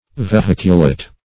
Search Result for " vehiculate" : The Collaborative International Dictionary of English v.0.48: Vehiculate \Ve*hic"u*late\, v. t. & i. To convey by means of a vehicle; to ride in a vehicle.